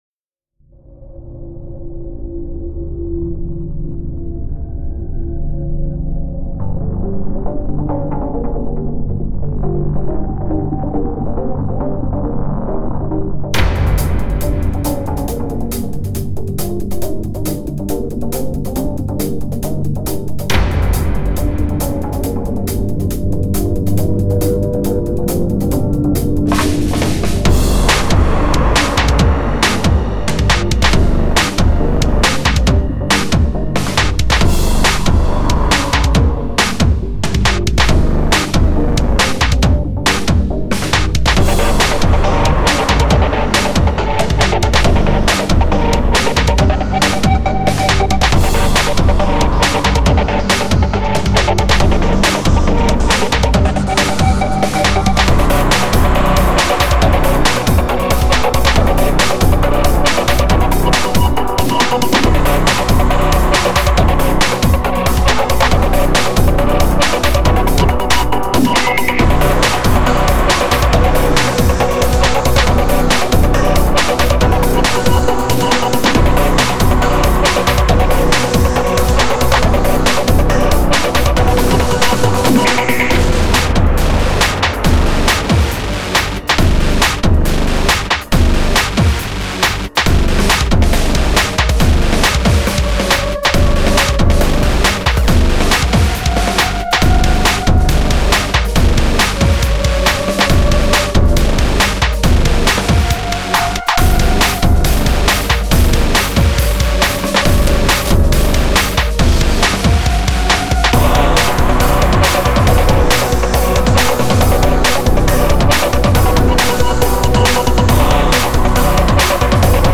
ELECTRONIC/FUTURISTIC
Escape the complex to this electronic futuristic beat